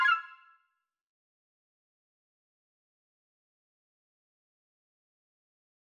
obsydianx-interface-sfx-pack-1
error_style_4_004.wav